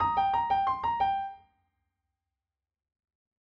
Blues Run for Piano Lick 2
piano-blues-run-snippet1.mp3